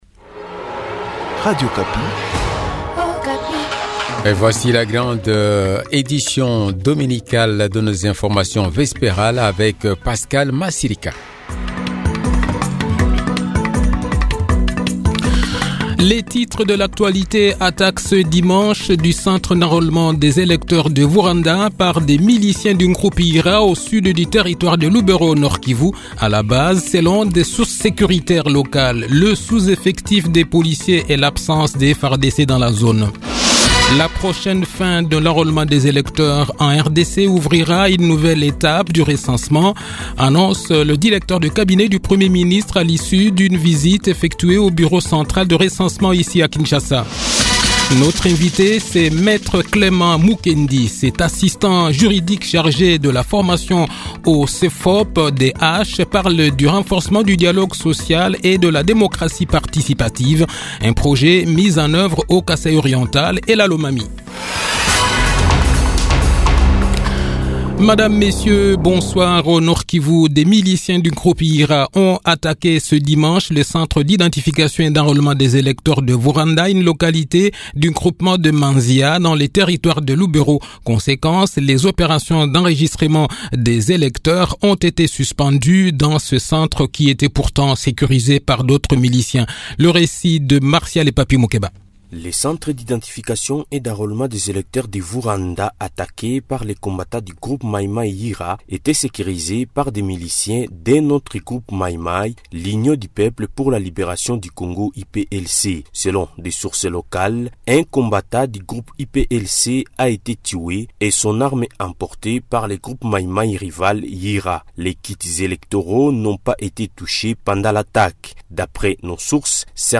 Le journal de 18 h, 2 avril 2023